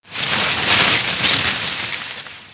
leaves2.mp3